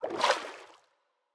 Minecraft Version Minecraft Version latest Latest Release | Latest Snapshot latest / assets / minecraft / sounds / mob / turtle / swim / swim4.ogg Compare With Compare With Latest Release | Latest Snapshot
swim4.ogg